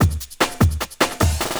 50 LOOP03 -L.wav